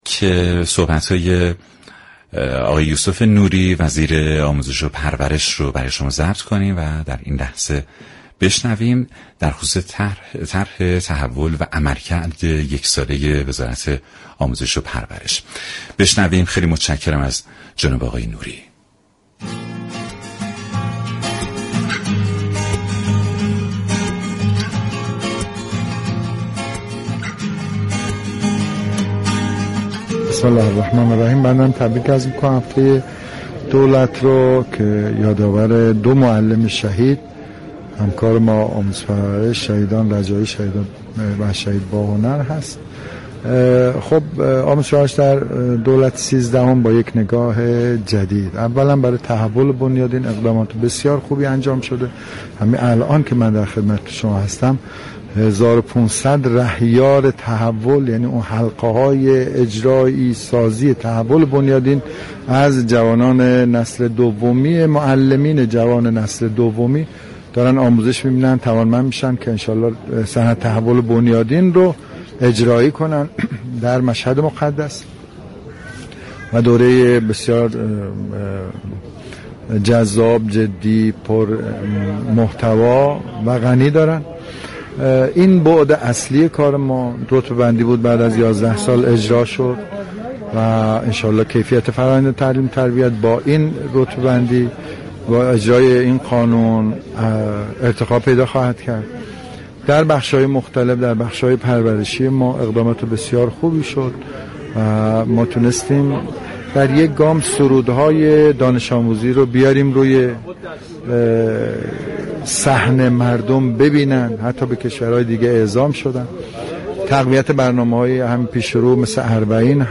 یوسف نوری، وزیر آموزش و پرورش در گفت‌و‌گو با رادیو جوان در برنامه «كافه رادیو» گفت: دولت سیزدهم در حوزه آموزش و پرورش گام‌های خوبی را برای تحول بنیادین برداشته است.